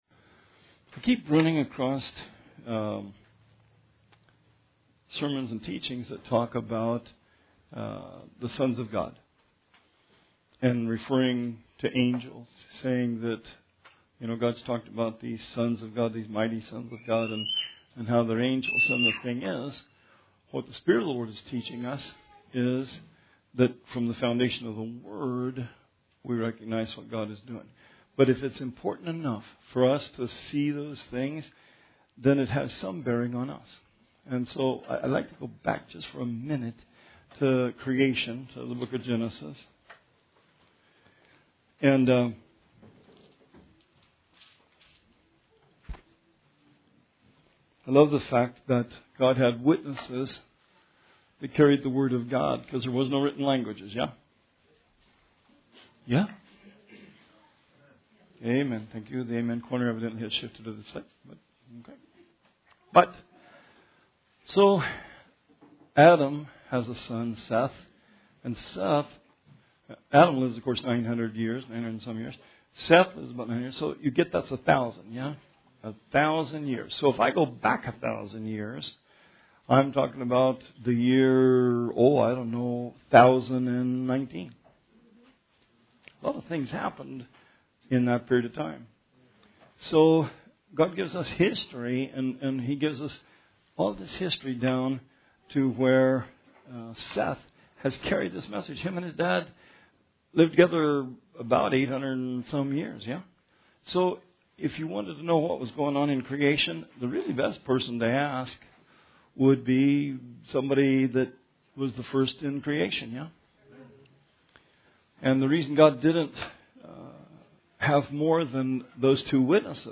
Bible Study 3/13/19